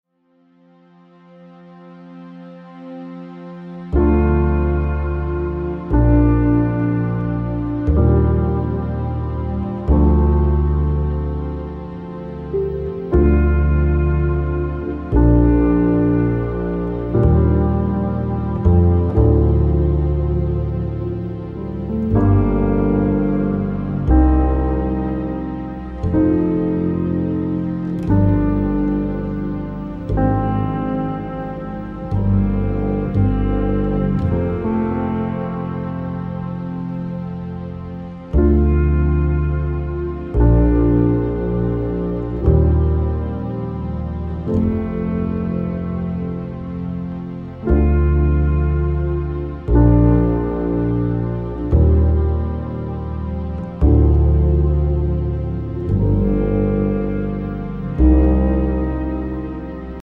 The mysterious and bone-chilling score